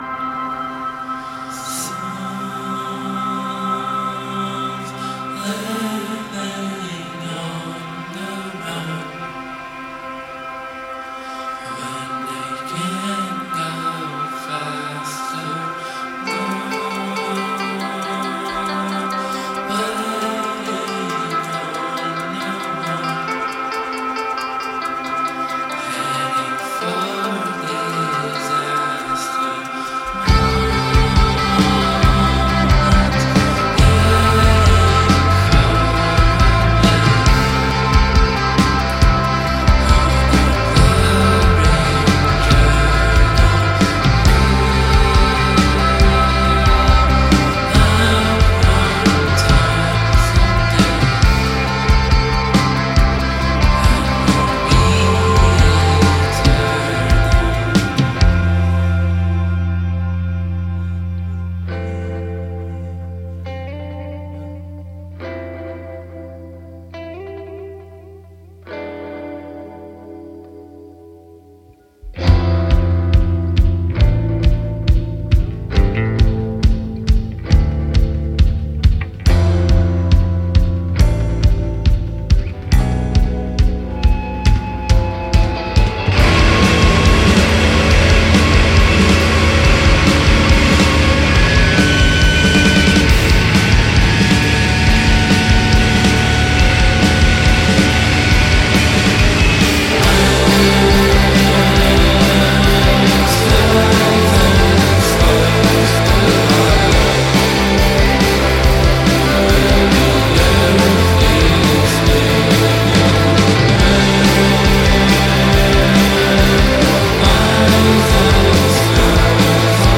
Lisbon Portugual based shoegaze band